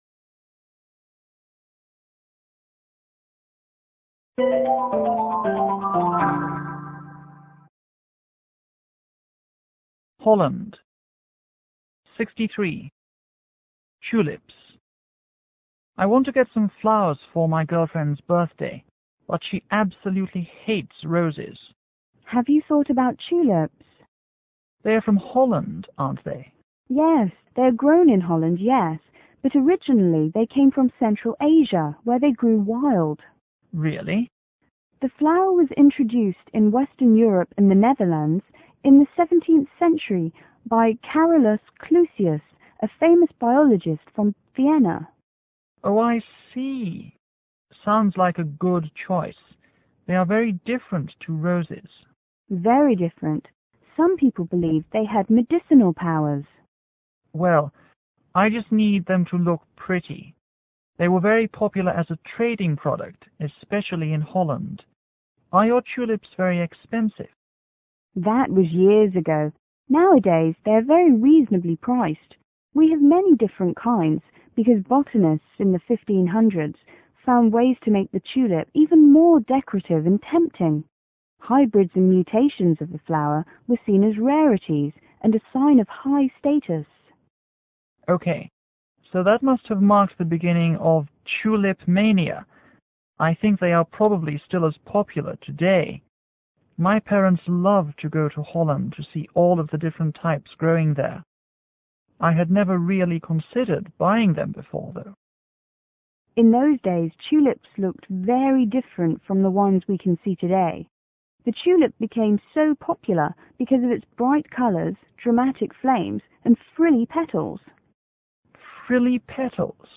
M: Man            W: Woman